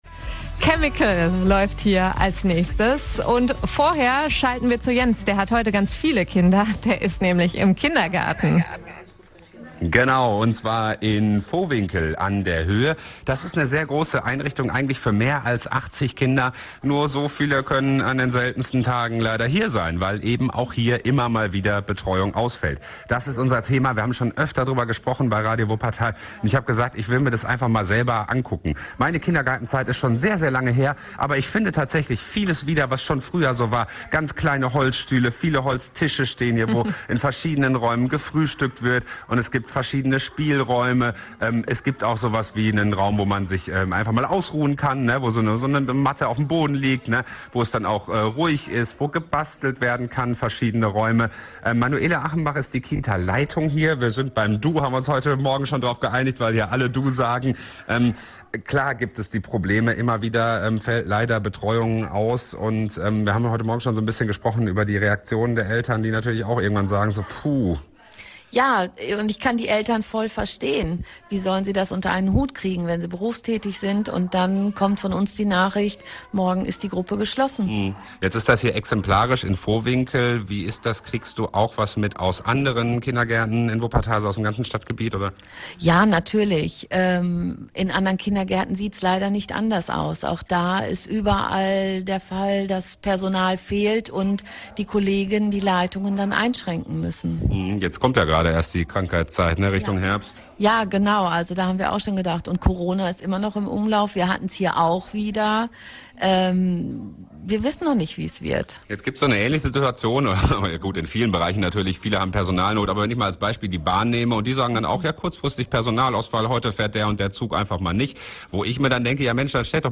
Interview Teil 2 mit der Kita-Leiterin
_-_kita_3_interview_wie_reagieren_eltern.mp3